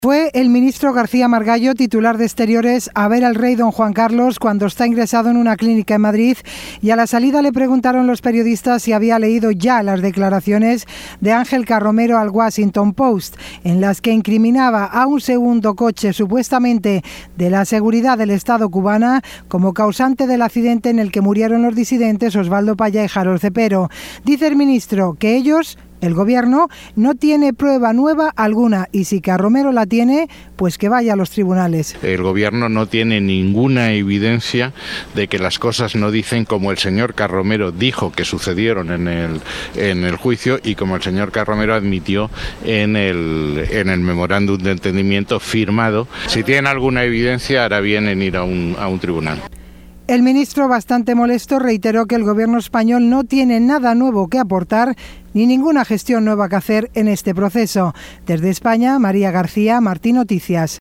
Declaraciones del Ministro de Exteriores de España sobre caso Carromero